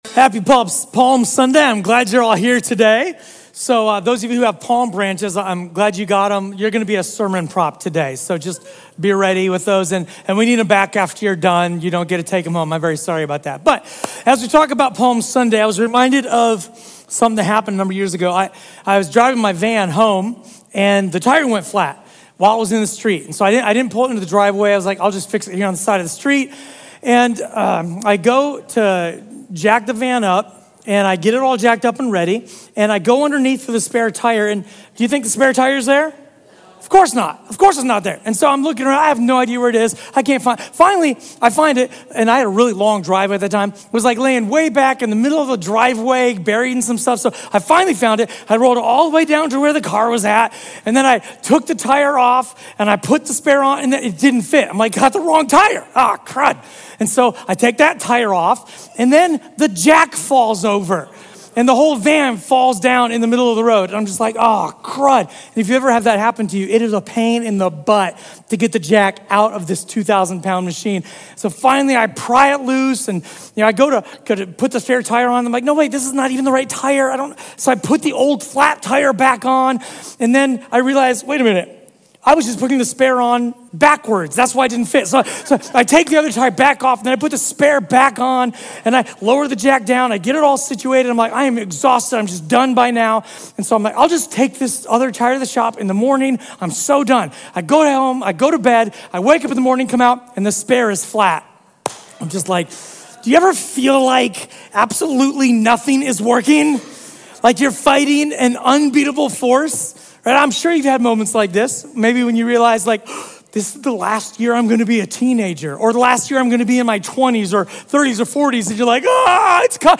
A sermon from the series "Revolution Sermon."